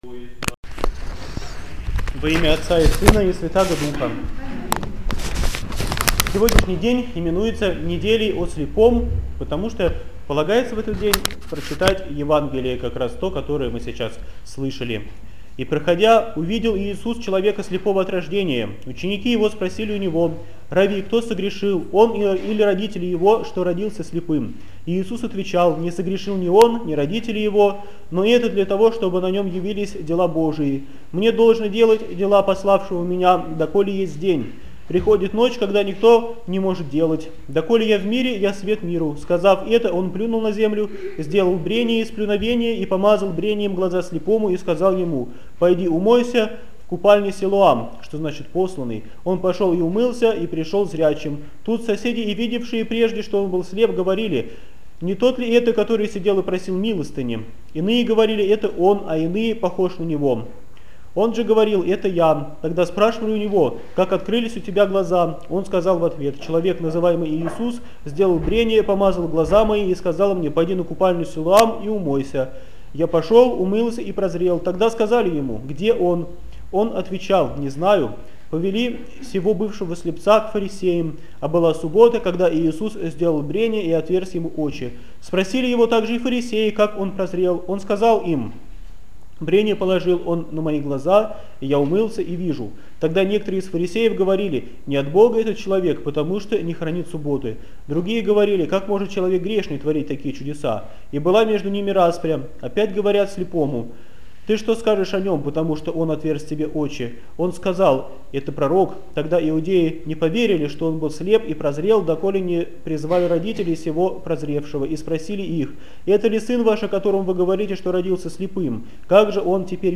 БОГОЛЮБСКИЙ ХРАМ ПОСЕЛОК ДУБРОВСКИЙ - Проповедь Неделя о слепом 2013